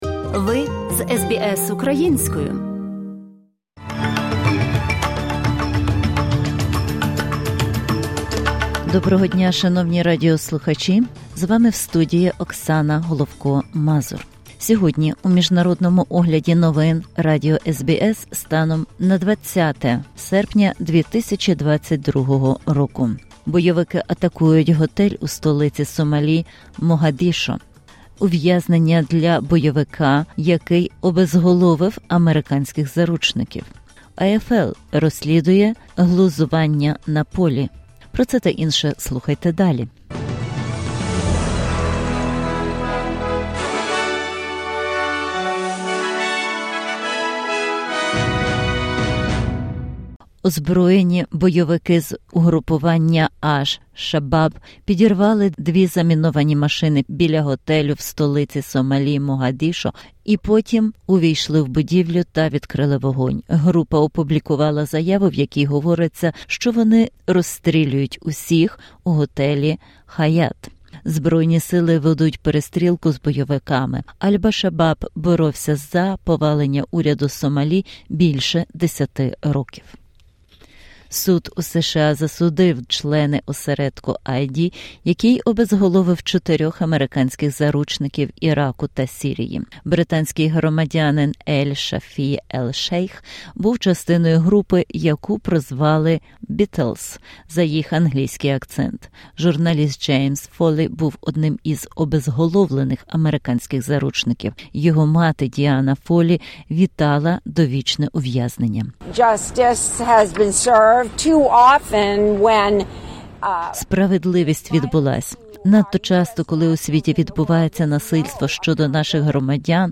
In this bulletin: Militants attack a hotel in the Somali capital Mogadishu. A life sentence for an IS militant. AFL set to investigate an on-field taunt.